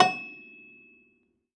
53w-pno14-E5.wav